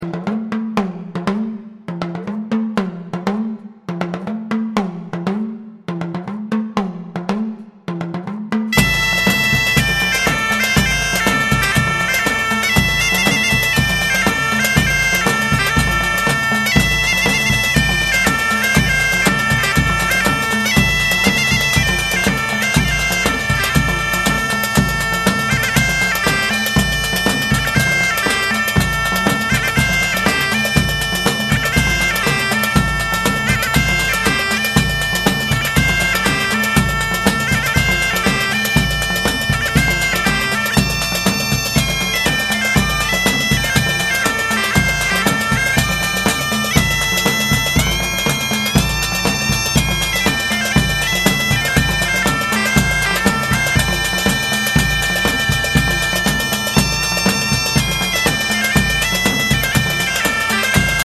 MittelatlerJazz und Weltmusik